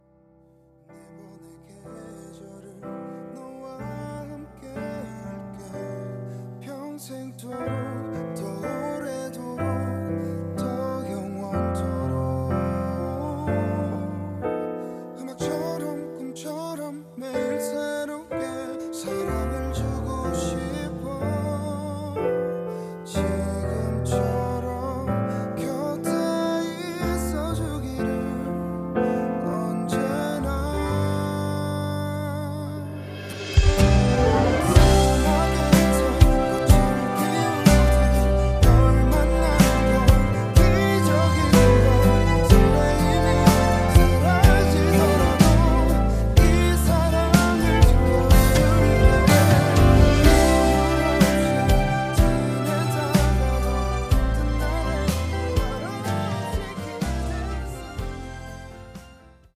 음정 -1키 4:19
장르 가요 구분 Voice Cut